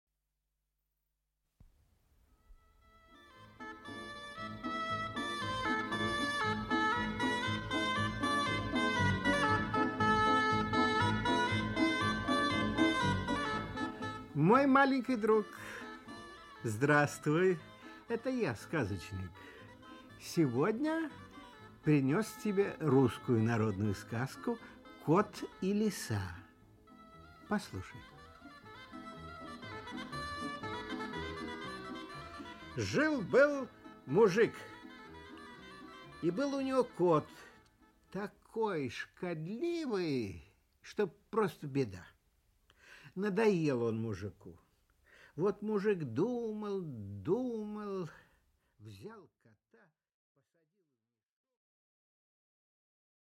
Aудиокнига Кот и Лиса Автор Народное творчество Читает аудиокнигу Николай Литвинов.